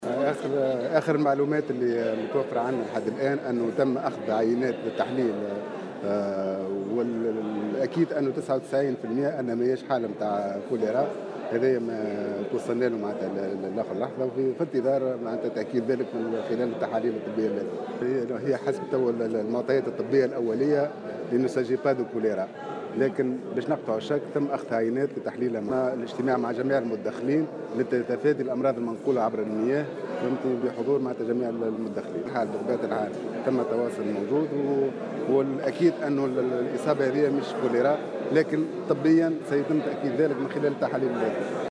وأضاف العريبي في تصريح اليوم لمراسل "الجوهرة أف أم" على هامش الندوة الدورية للولاة، أن آخر المعطيات الطبية تستبعد بنسبة 99 بالمائة أن تكون الوفاة ناجمة عن "الكوليرا".